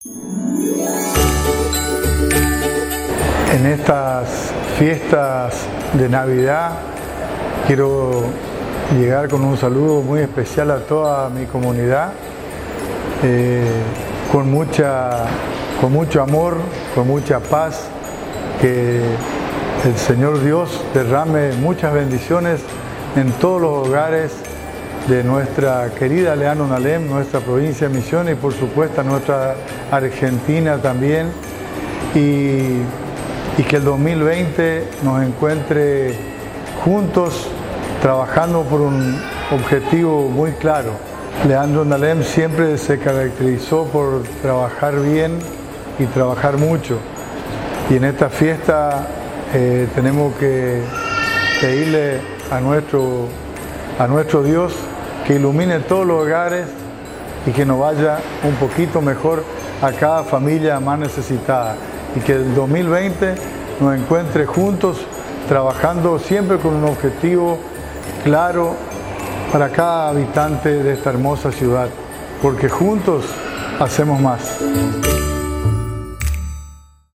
Salutaciones y buenos deseos de Intendentes, Concejales y funcionarios Municipales - Agencia de Noticias Guacurari
Audio: Valdy Wolenberg intendente de Leandro N. Alem Maria Euguenia Safran, Intendente de Apóstoles Ramón Gerega Intendente Tres Capones Valdemar Wolenberg Intendente Leandro N Alem